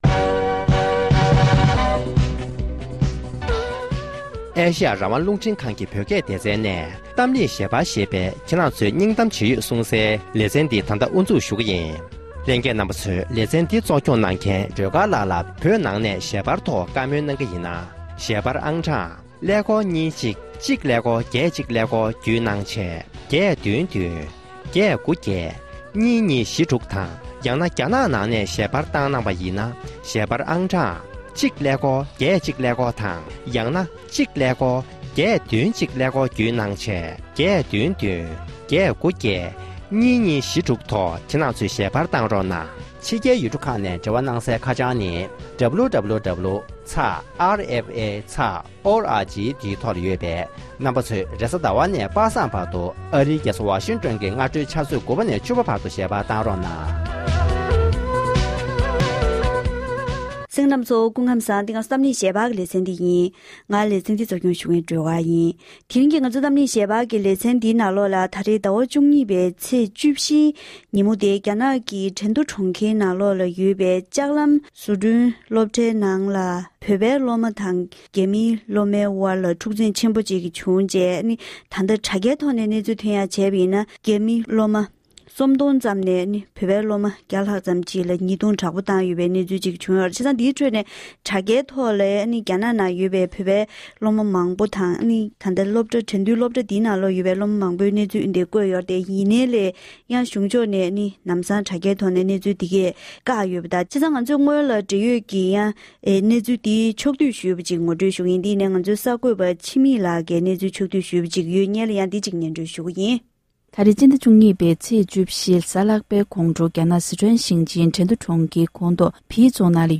༄༅༎དེ་རིང་གི་གཏམ་གླེང་ཞལ་པར་གྱི་ལེ་ཚན་ནང་དུ་རྒྱ་ནག་གི་གྲོང་ཁྱེར་གྲེན་རྡུའི་ནང་ཡོད་པའི་མཐོ་སློབ་ཞིག་གི་ནང་རྒྱ་མིའི་སློབ་མ་སྟོང་ཕྲག་གིས་བོད་པའི་སློབ་མ་བརྒྱ་ཕྲག་ལ་ཉེས་རྡུང་ཚབས་ཆེན་བཏང་བའི་གནད་དོན་དེའི་ཁྲོད་ནས་མི་རིགས་གནད་དོན་ཐོག་བགྲོ་གླེང་ཞུས་པ་ཞིག་གསན་རོགས༎